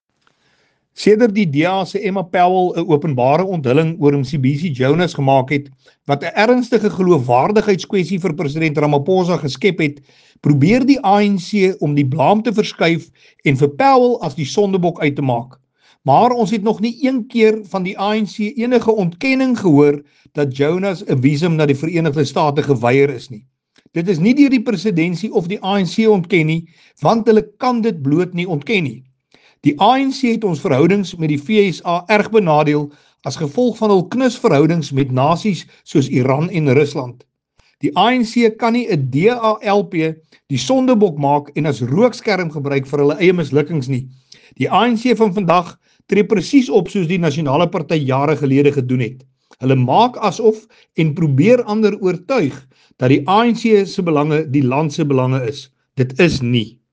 Issued by Willie Aucamp MP – DA National Spokesperson
Afrikaans soundbites by Willie Aucamp.
Afrikaans-Willie-Aucamp.mp3